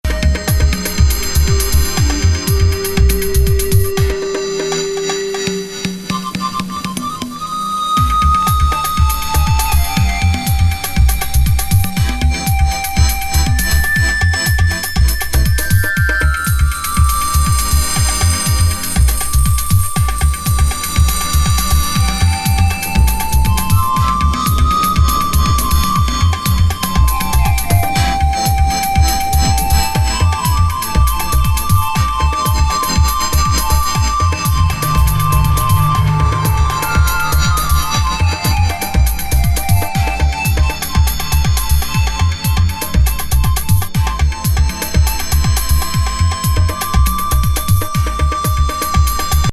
DEEP HOUSE!日本プレス盤。